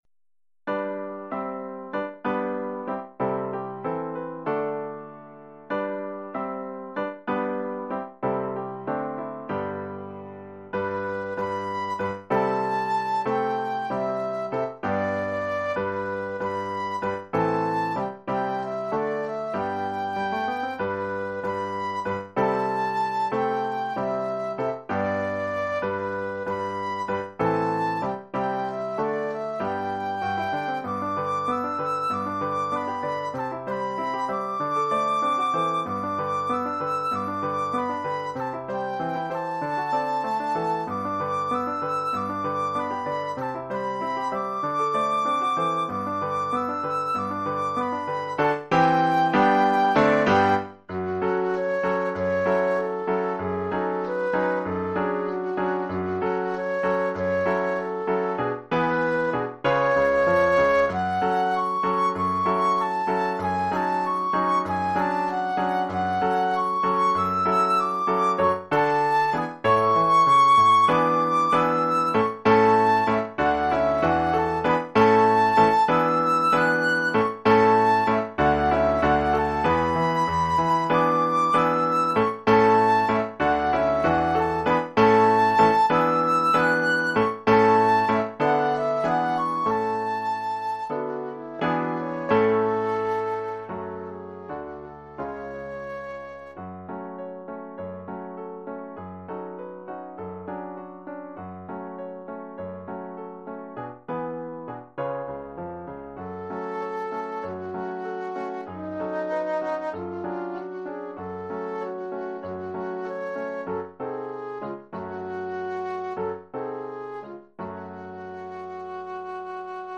1 titre, flûte et piano : conducteur et partie de flûte
Oeuvre pour flûte et piano..